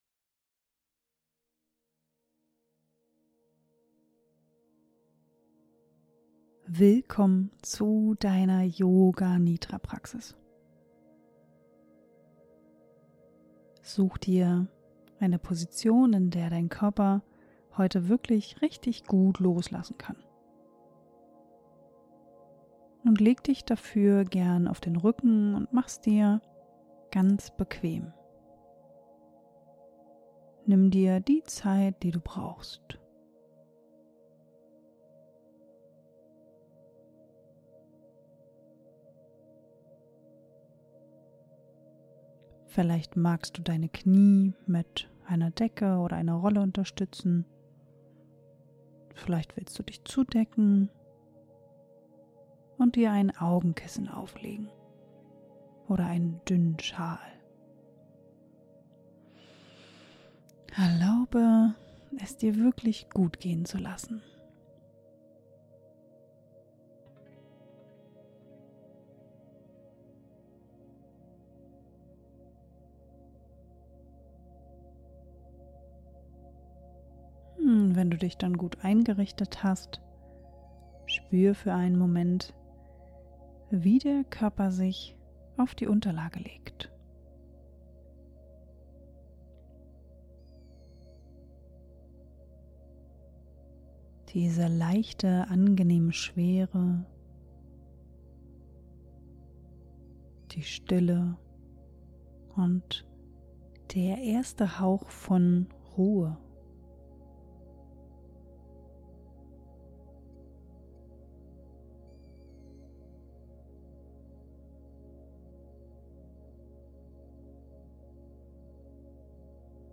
In der Session führe ich dich sanft durch deinen Körper, durch Atemräume, in die Stille hinein und wieder hinaus. Es geht um Loslassen, um Spürarbeit, um Nervensystem-Regeneration.